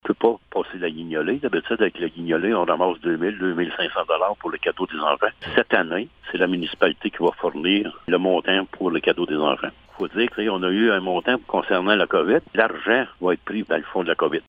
Du côté de Blue Sea, la municipalité organisait une guignolée pour amasser des fonds pour cette activité de Noël, mais comme la guignolée n’aura pas lieu cette année, l’argent sera pris à même les fonds de la Municipalité. Écoutons le maire de Blue Sea, Laurent Fortin : Épisode Laurent Fortin - 23 novembre 2020